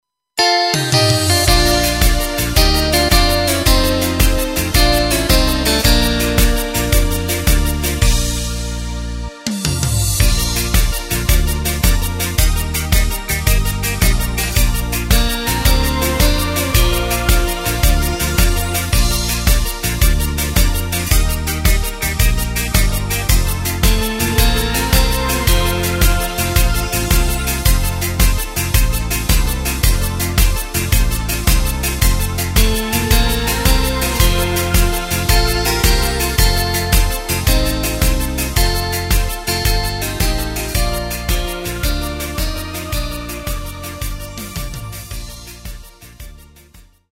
Takt:          4/4
Tempo:         110.00
Tonart:            Bb
Schlager aus dem Jahr 2016!
Playback mp3 Demo